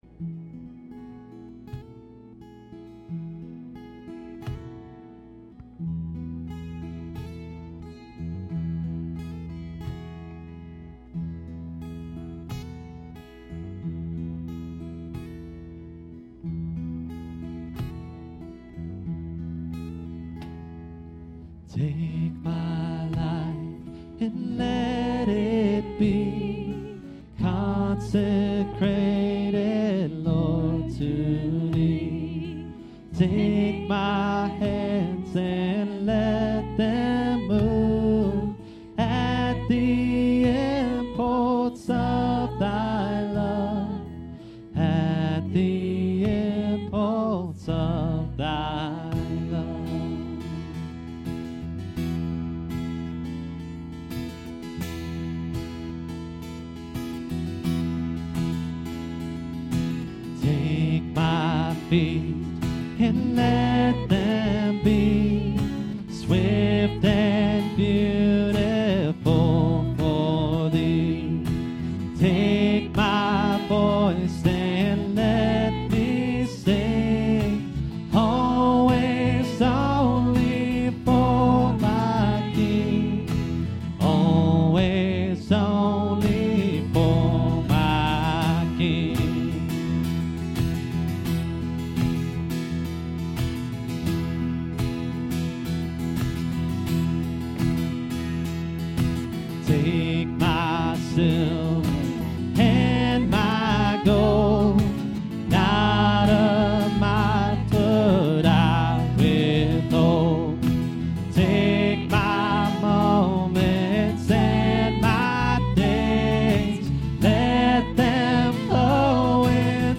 Bible Text: Colossians 3:2 | A night of worship where multiple churches came together to celebrate our Lord and Savior!